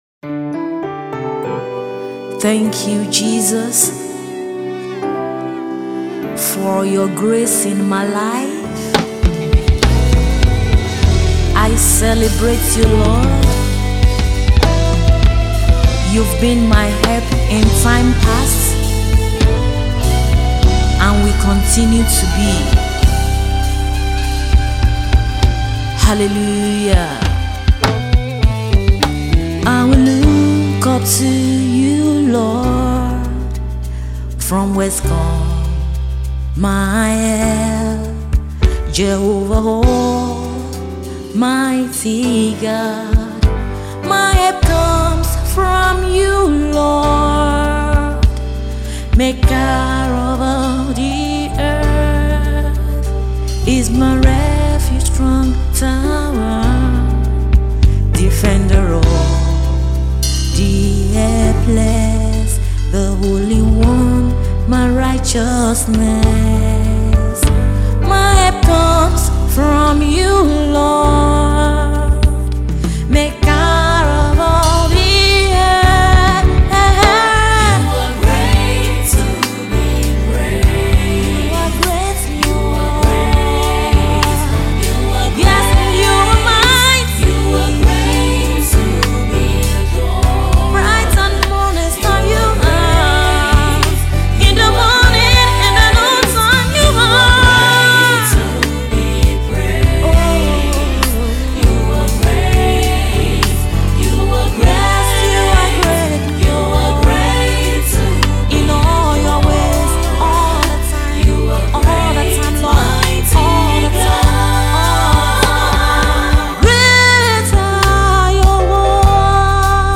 worship piece